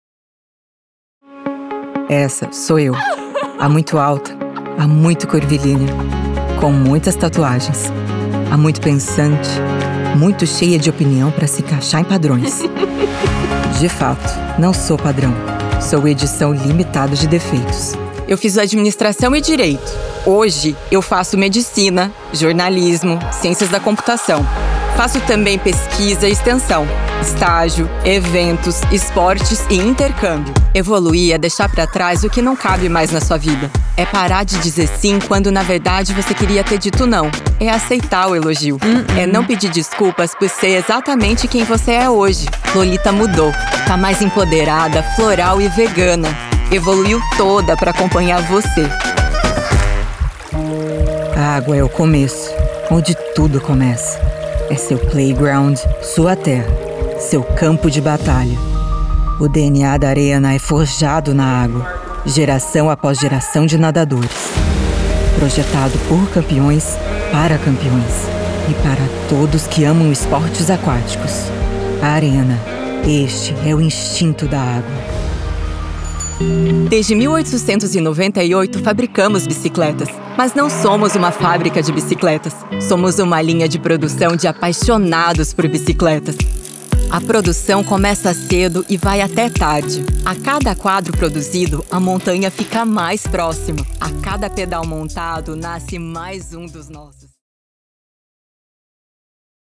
Brazilian Portuguese voiceover, Brazilian Portuguese female voiceover, young adult voice, confident voice, soft voice.